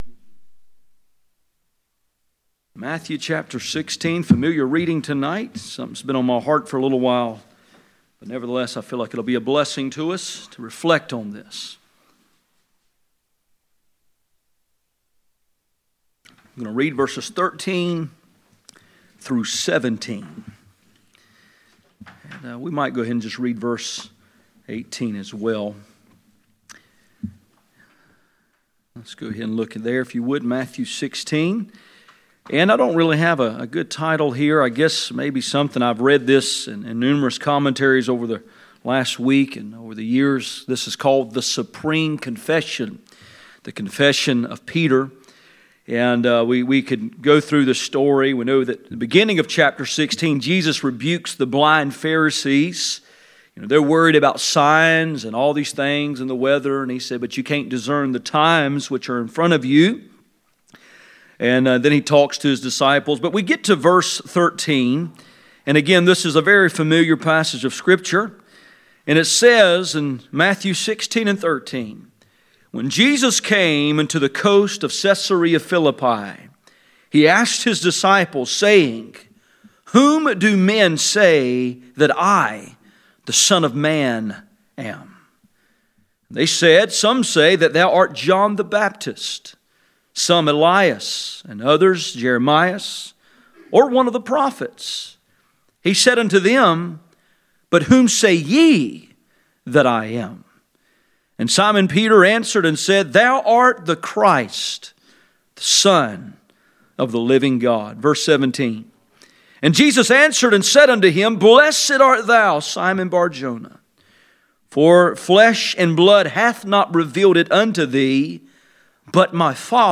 None Passage: Mark 16:13-18 Service Type: Sunday Evening %todo_render% « Practical Holiness